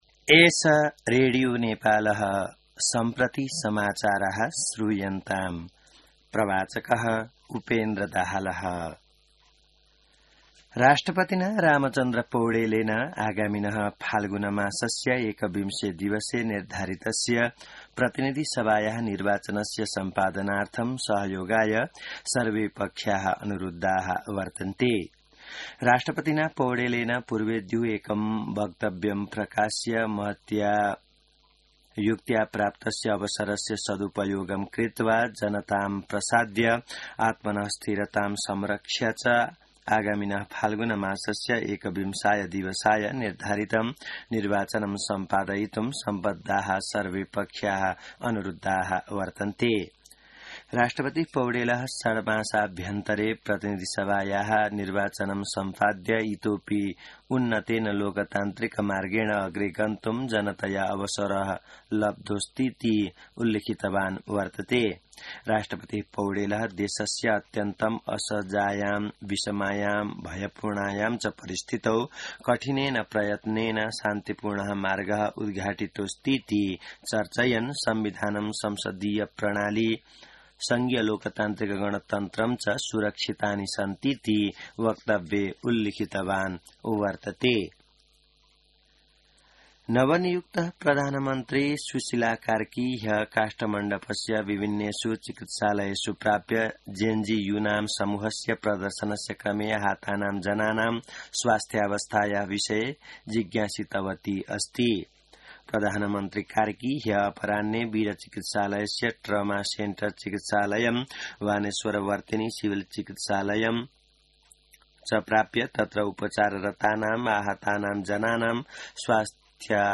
संस्कृत समाचार : २९ भदौ , २०८२